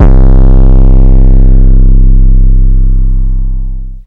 808 [ Antidote ].wav